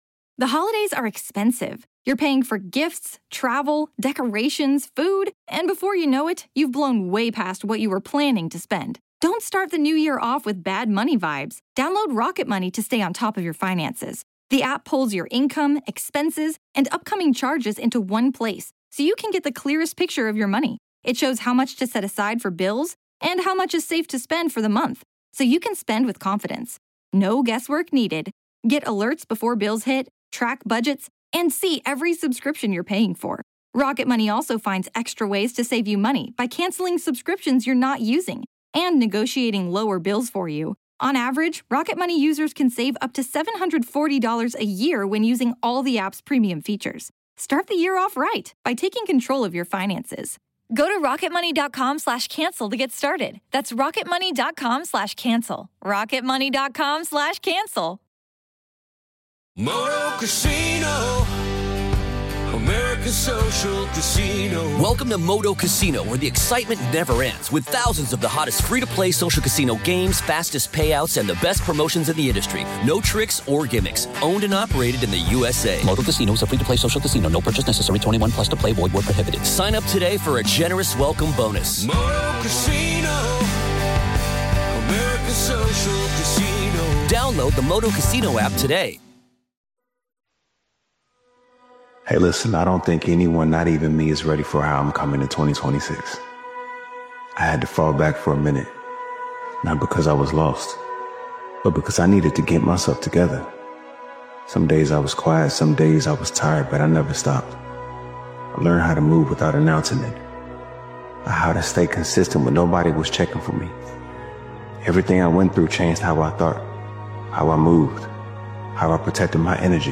Powerful Motivational Speech Video is a bold and defiant motivational video created and edited by Daily Motivations.